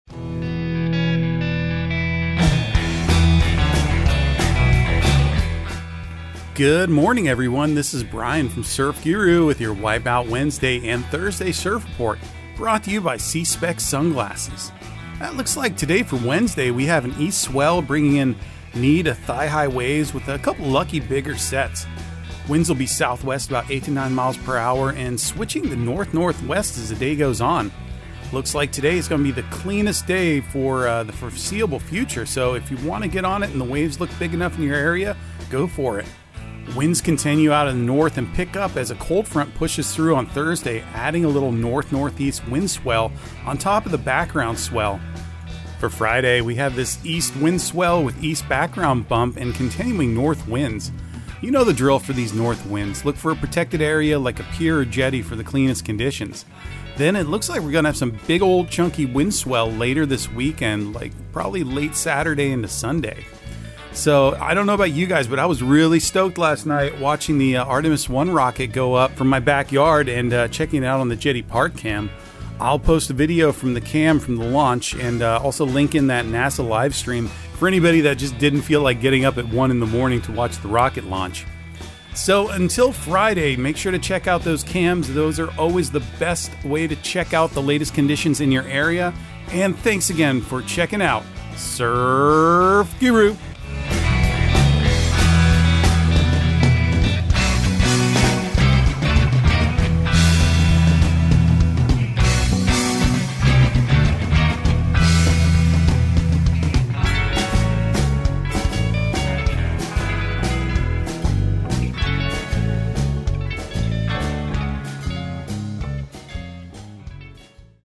Surf Guru Surf Report and Forecast 11/16/2022 Audio surf report and surf forecast on November 16 for Central Florida and the Southeast.